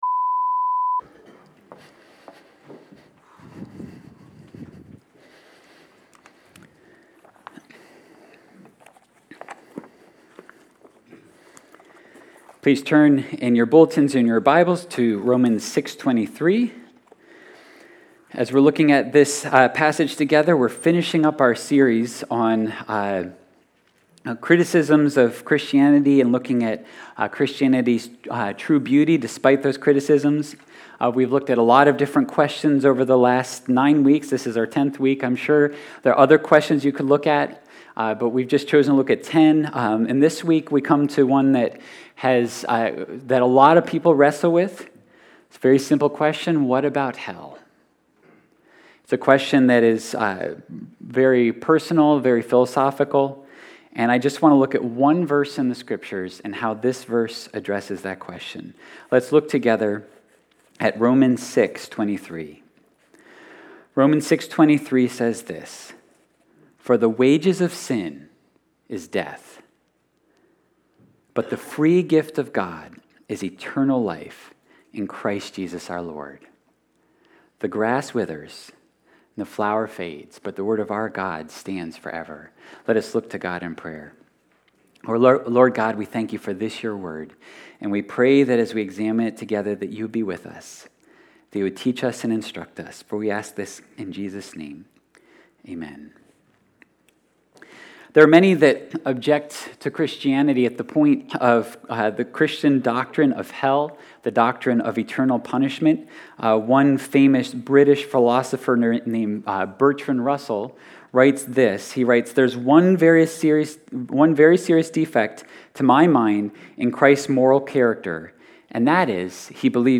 7.31.22-sermon-audio.mp3